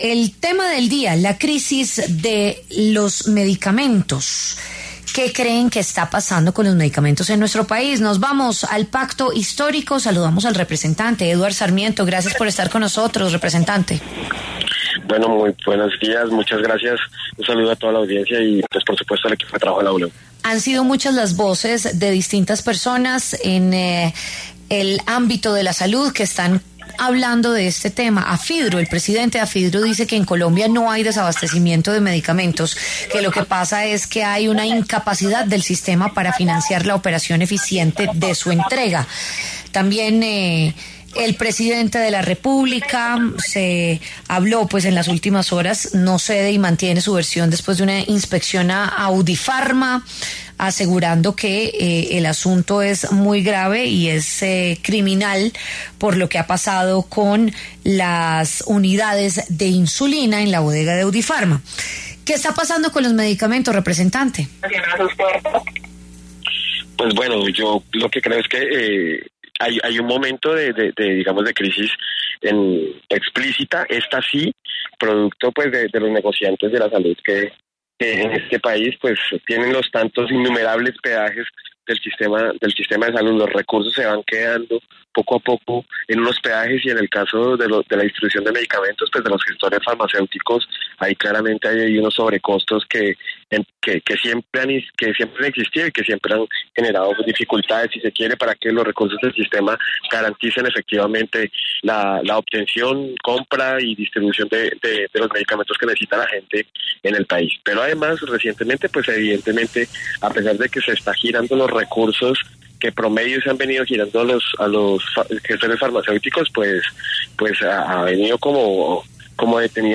En diálogo con La W, Eduard Sarmiento, representante a la Cámara por el Pacto Histórico, se refirió a la crisis por el desabastecimiento de medicamentos en Colombia.